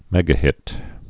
(mĕgə-hĭt)